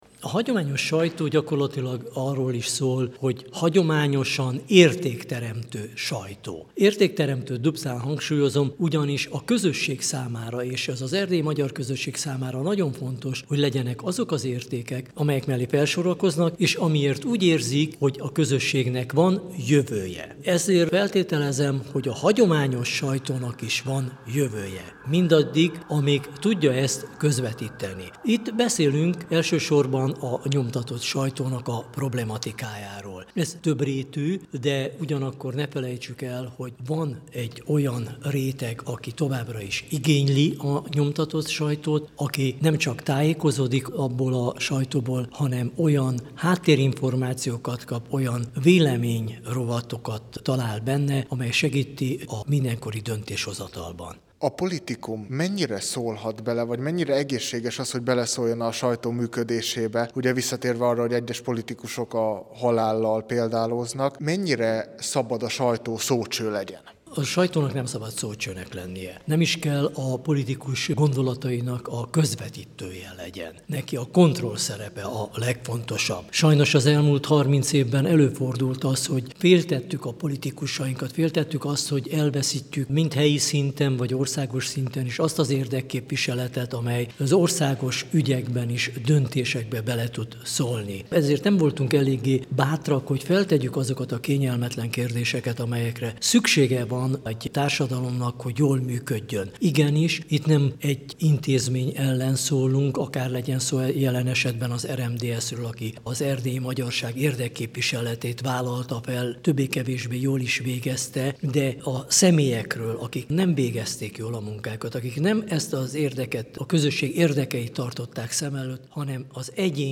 Kerekasztal-beszélgetés a politikum és a sajtó között